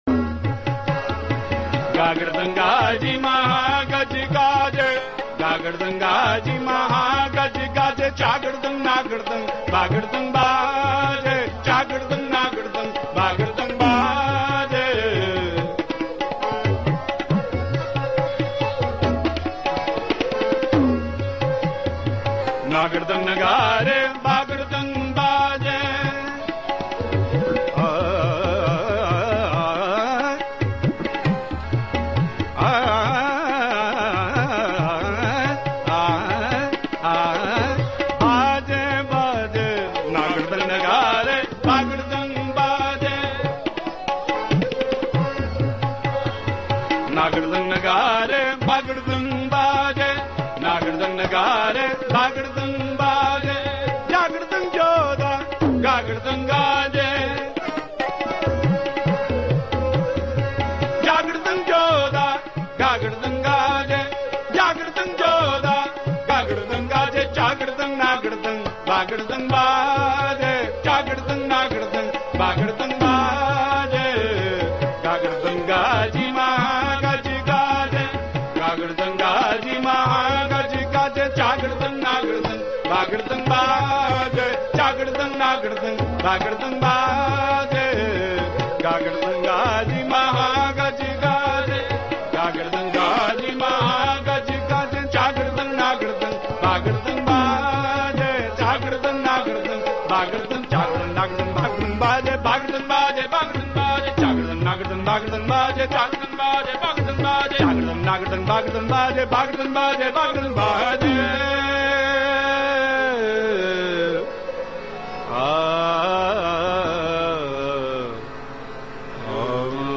Sri Dasam Granth Kirtan by Chardi Kala Jatha
sri-dasam-granth-kirtan-chardi-kala-jatha-from-harminder-sahib.mp3